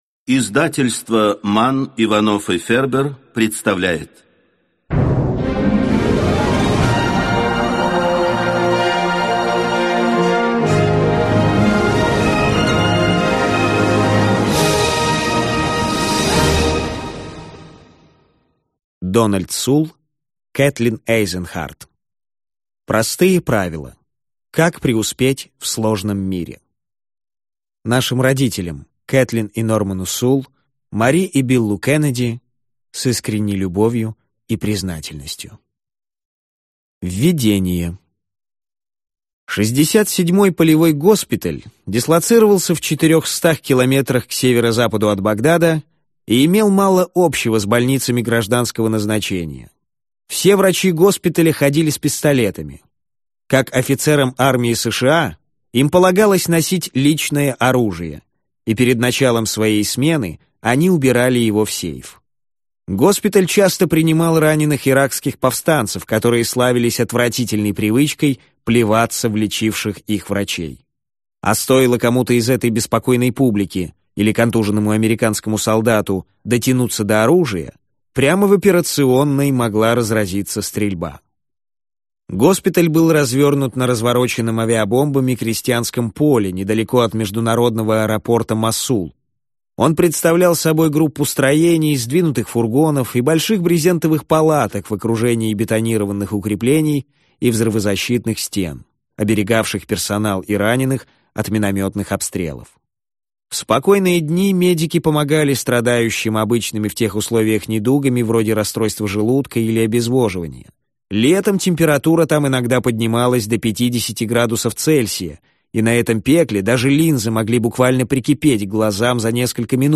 Аудиокнига Простые правила. Как преуспеть в сложном мире | Библиотека аудиокниг